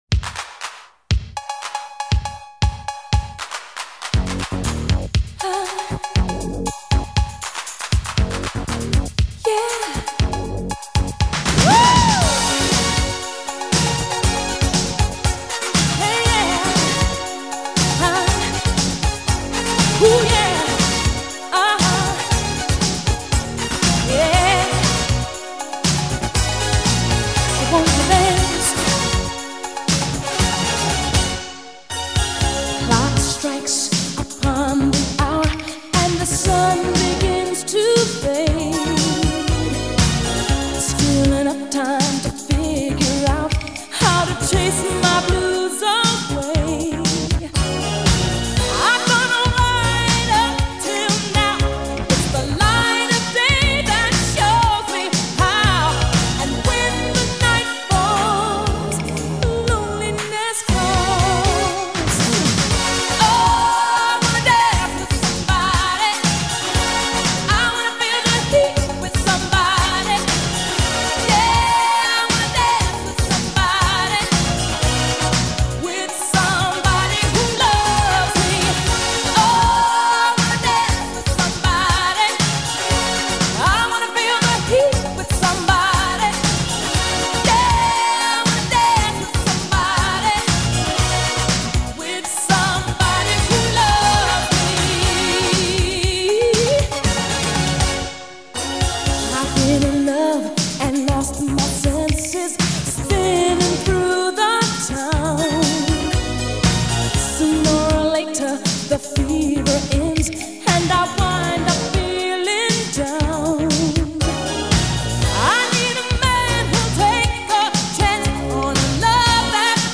Pop, R&B, Soul